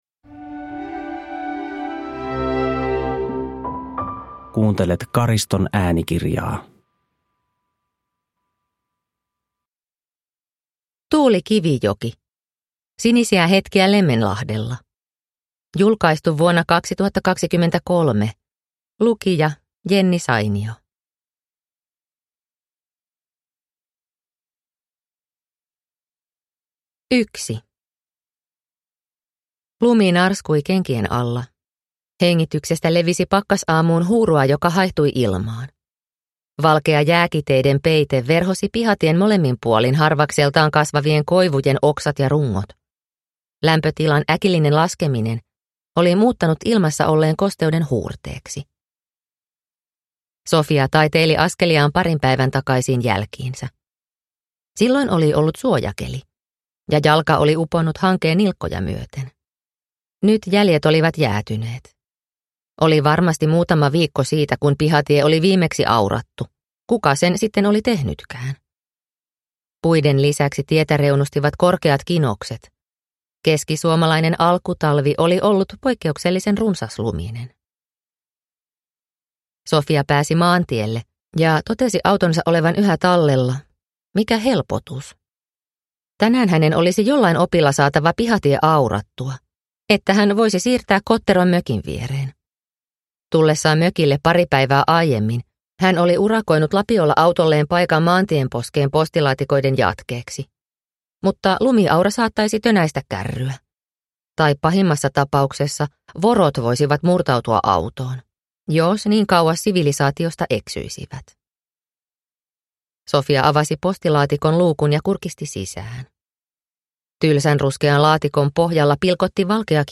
Sinisiä hetkiä Lemmenlahdella – Ljudbok – Laddas ner